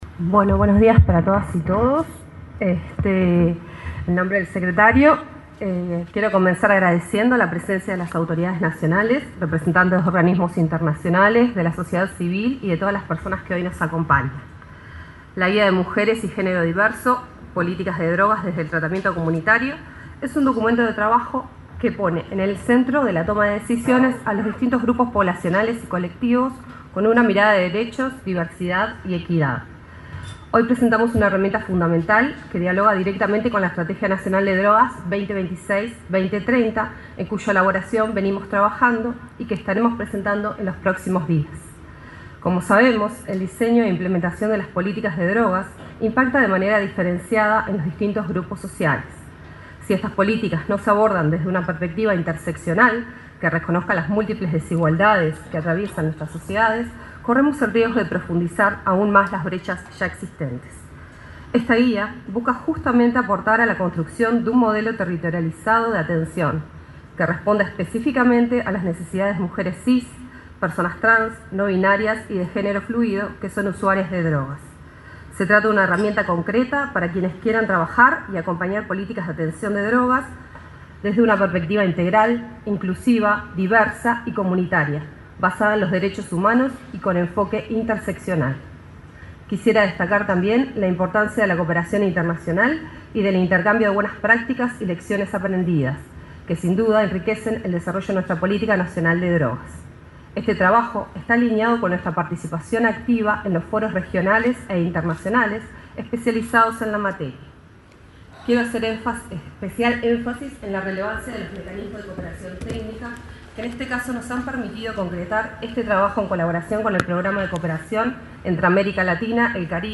se expresó durante la presentación de la guía